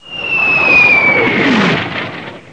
mortar.mp3